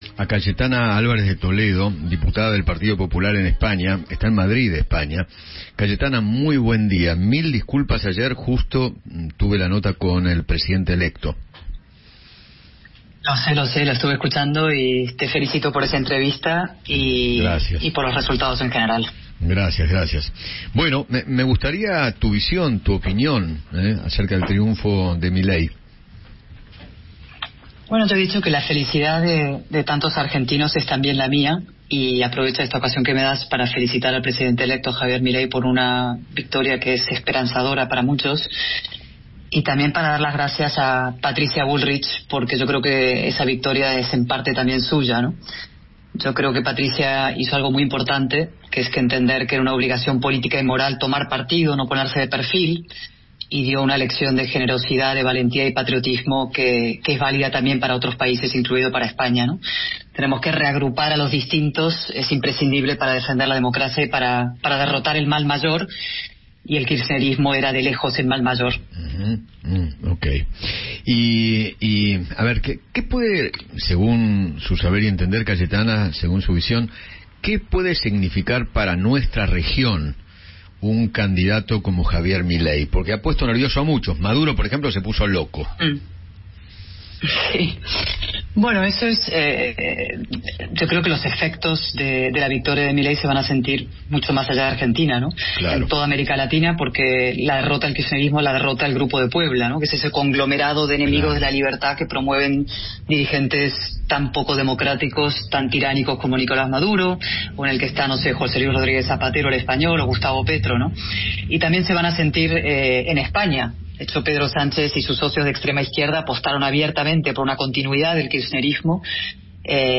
Cayetana Alvarez de Toledo, diputada del partido popular en España, dialogó con Eduardo Feinmann sobre el resultado del balotaje presidencial, que tuvo como ganador a Javier Milei.